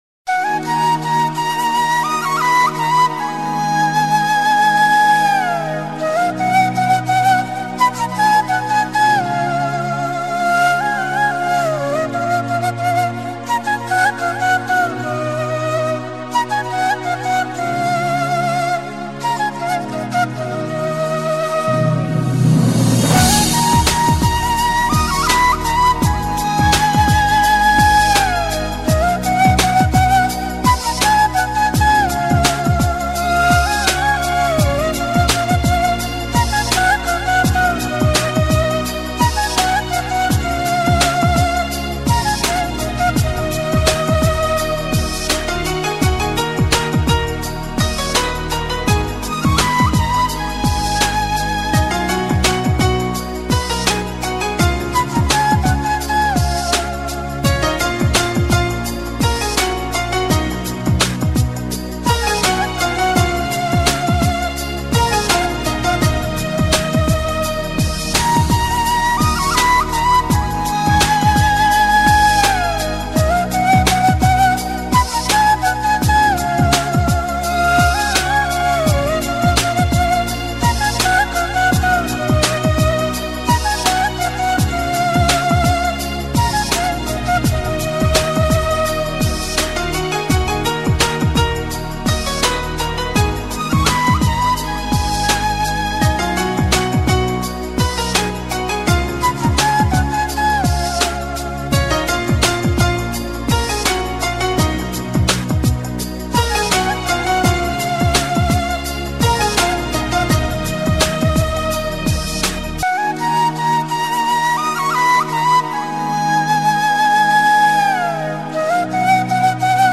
Ochen_krasivaja_Romanticheskaja_muzyka_instmentalnaja__im.mp3